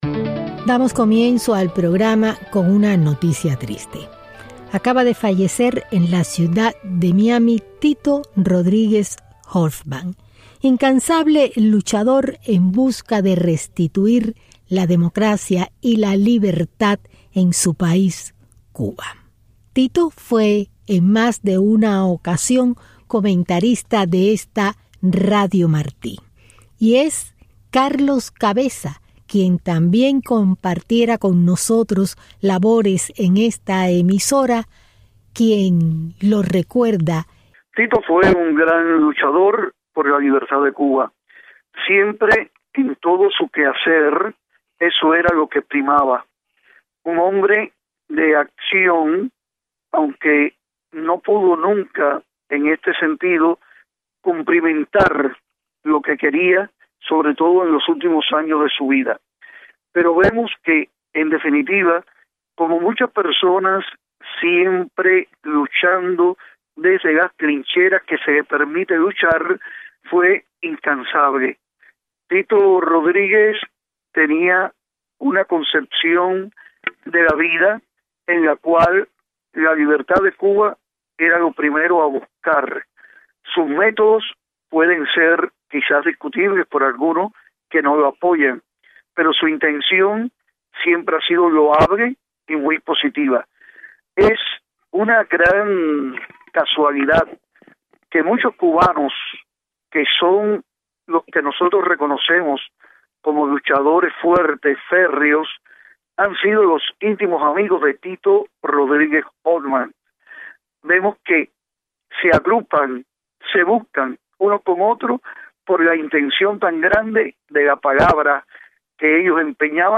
Entrevistas en Venezuela y España.